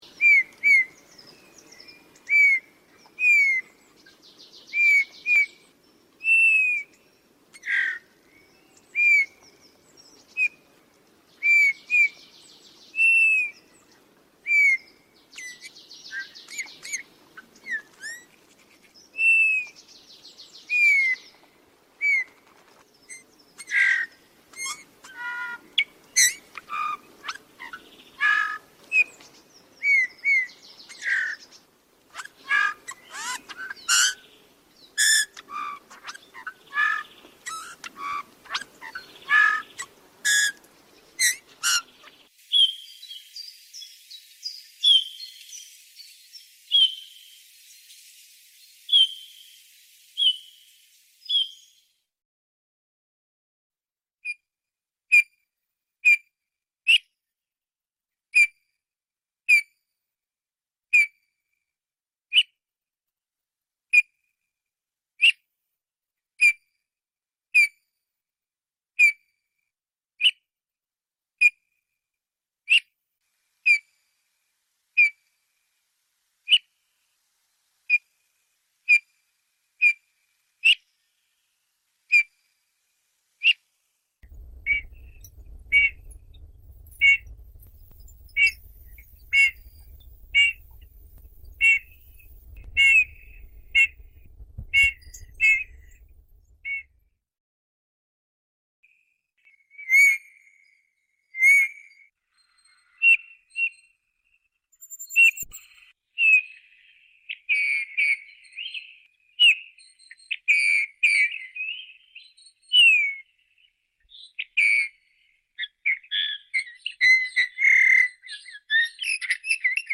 Şakrak Kuşu (Pyrrhula pyrrhula) Özellikleri